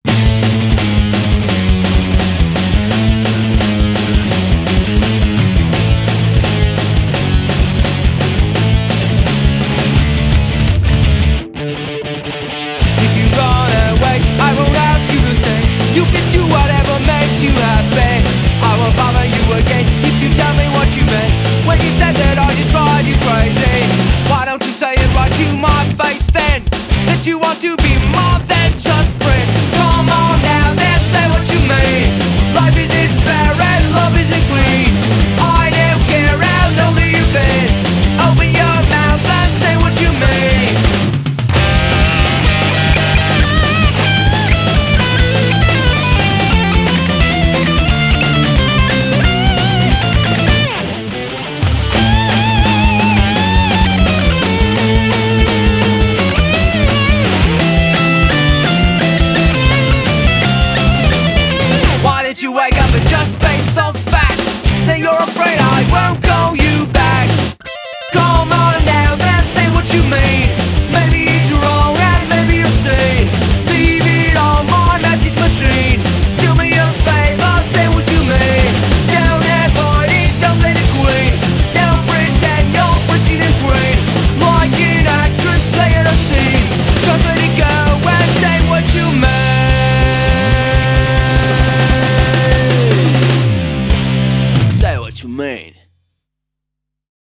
BPM170-183
コメント[MELO CORE]